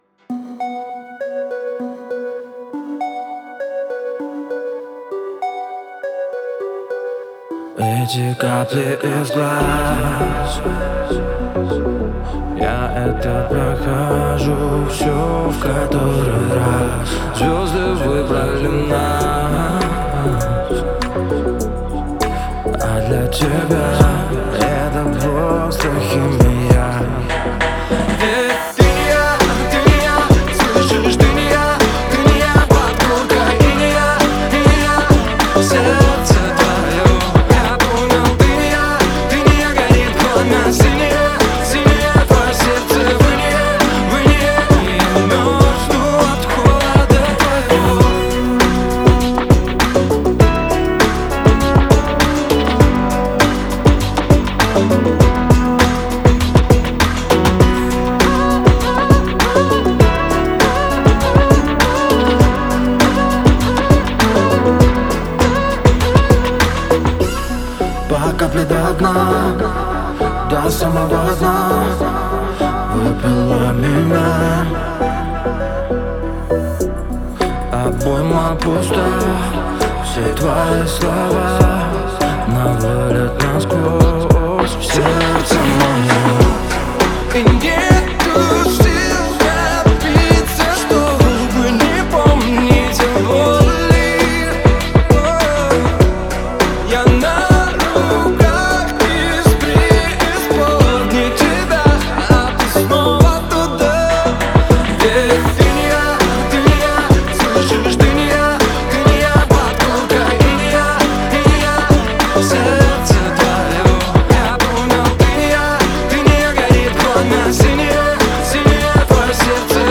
поп-рок